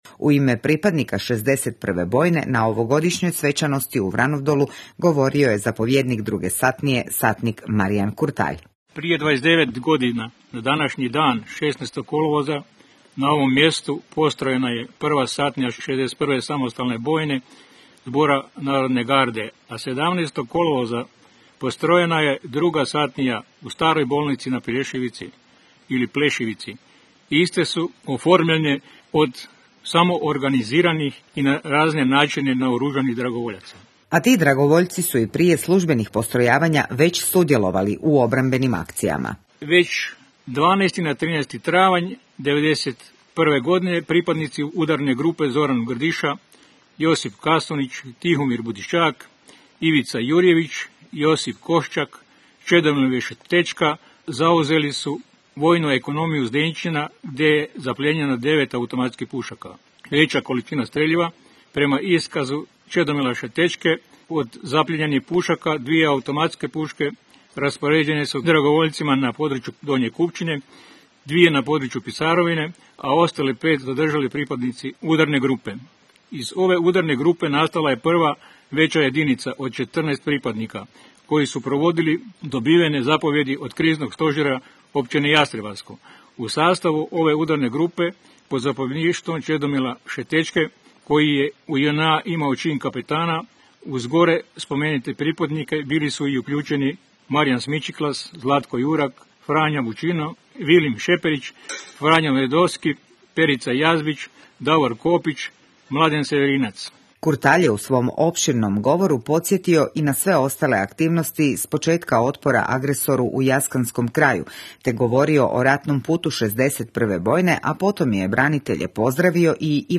Odavanjem počasti poginulim hrvatskim braniteljima u nedjelju 16. kolovoza obilježena je 29. obljetnica prvog postrojavanja 61. bojne Zbora narodne garde Republike Hrvatske u kamenolomu Vranov Dol.
U ime domaćina i organizatora svečanosti u Vranov Dolu, grada Jastrebarskog, govorio je zamjenik gradonačelnika Stipe Bučar.